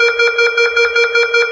Звук часто пикающий сигнал тревоги.